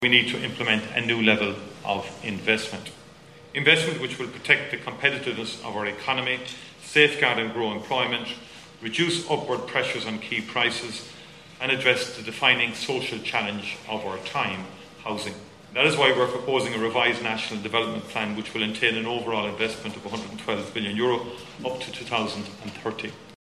Taoiseach Micheál Martin says housing is the key challenge facing the country for the rest of the decade…………….